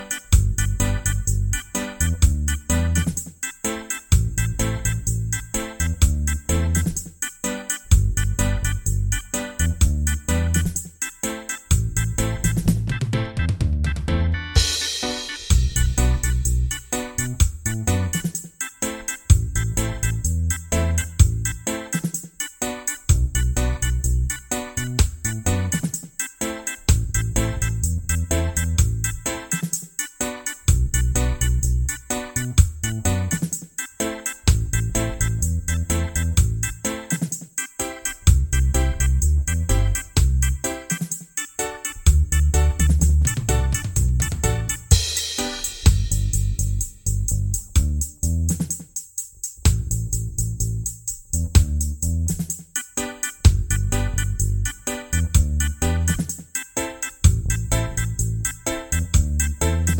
Minus Main Guitar For Guitarists 3:46 Buy £1.50